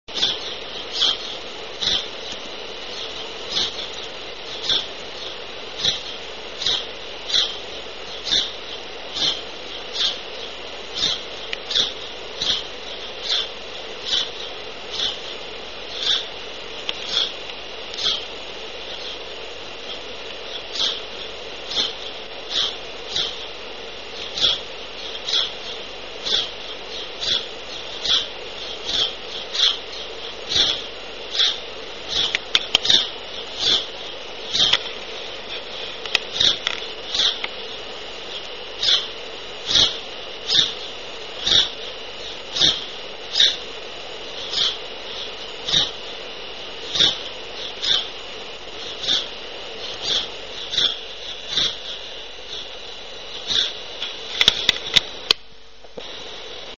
Pardal-comum
Passer domesticus
Pardal.mp3